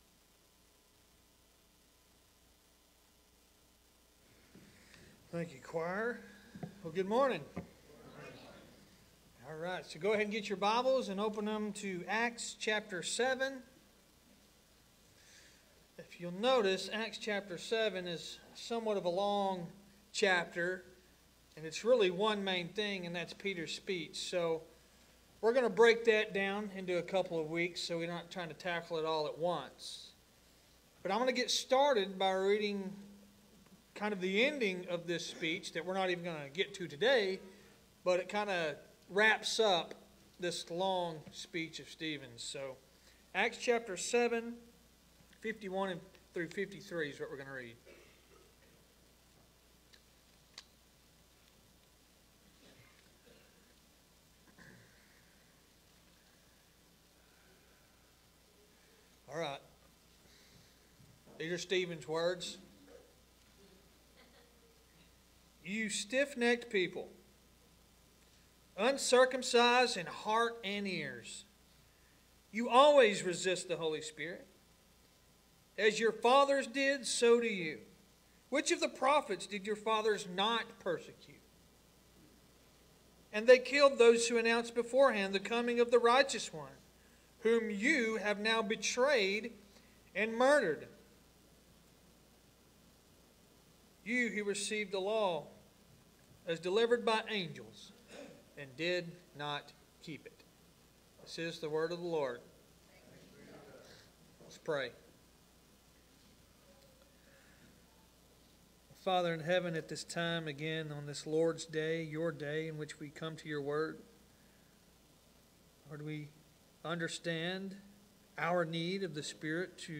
Sermons | Lake Athens Baptist Church